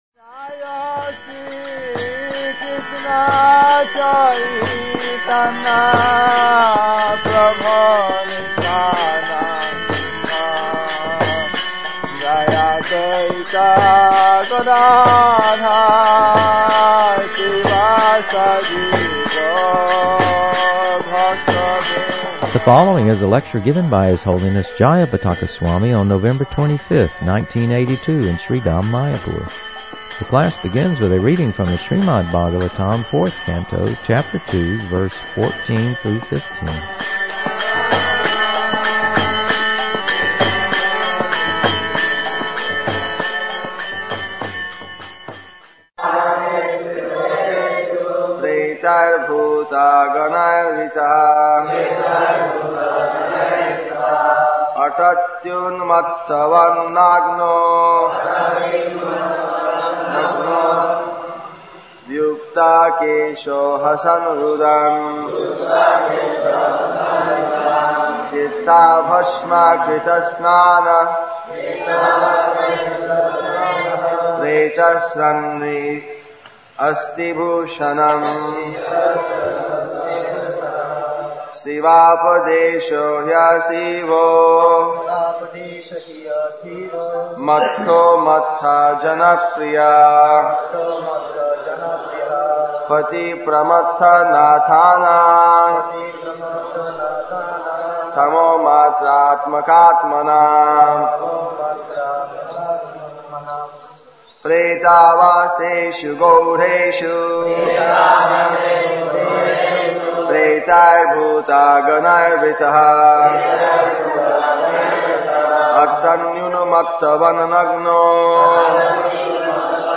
The following is a lecture
The class begins with a reading from the Srimad-Bhagavatam, 4th Canto, Chapter 2, Verse 14 through 15.